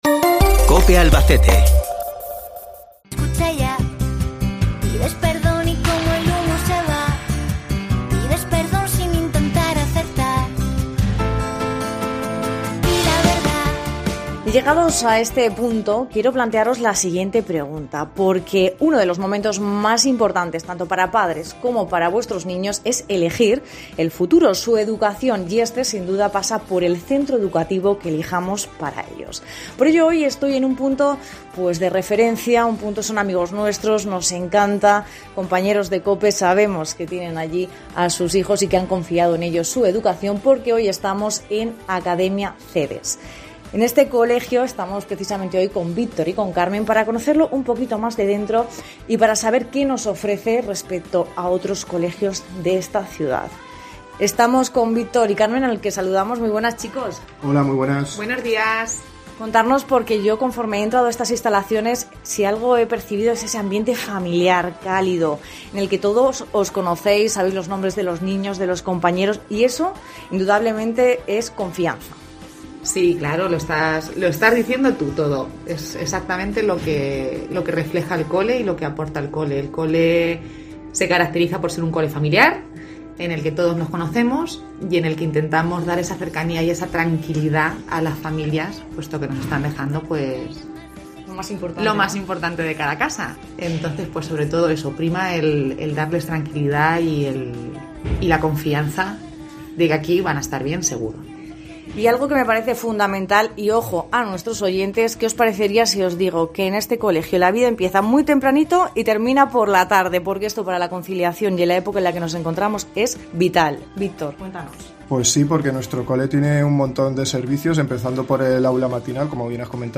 Entrevista Centro Concertado Academia Cedes